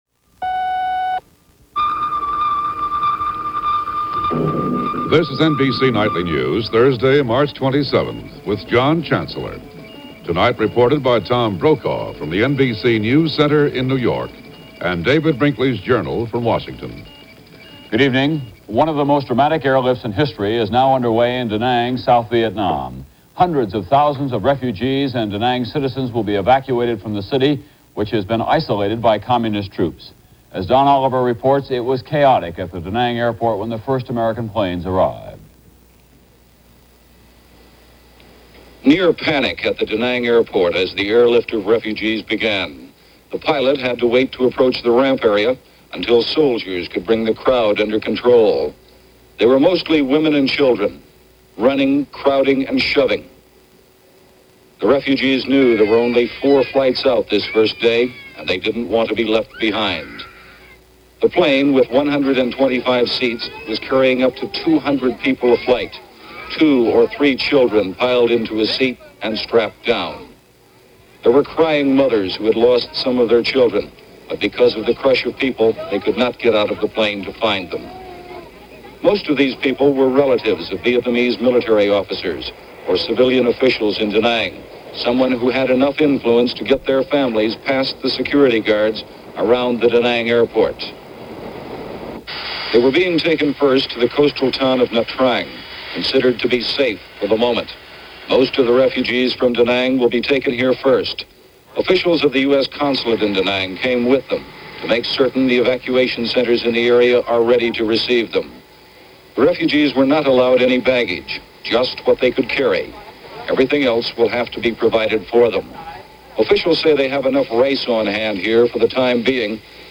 Vietnam: Escape From Danang - March 27, 1975 - news of last flight out from Danang airbase, Vietnam - NBC Nightly News - John Chancellor